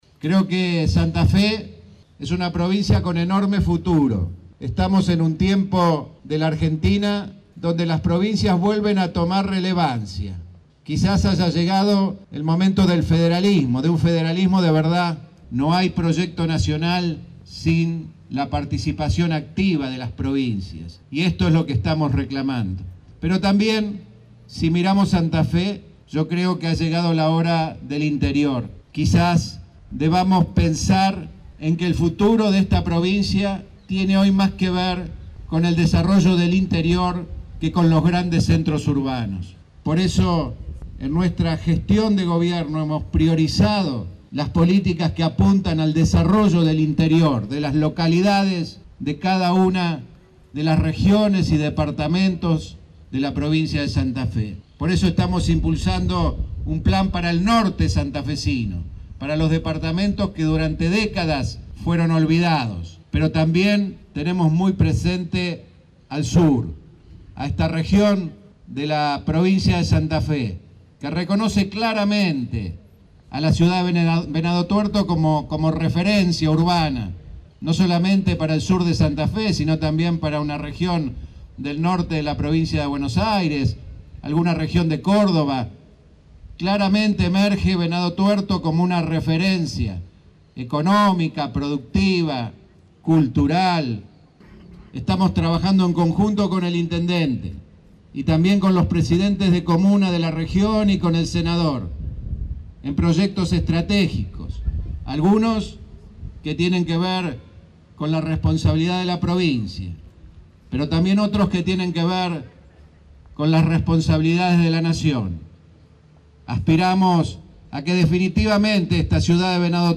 El gobernador de Santa Fe, Miguel Lifschitz, encabezó este martes en Venado Tuerto, el acto central en conmemoración de los 132° de la fundación de la ciudad.